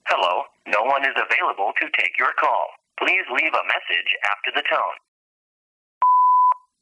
audacity beep data raw sound effect free sound royalty free Sound Effects